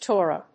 音節To・ra 発音記号・読み方
/tˈɔːrə(米国英語)/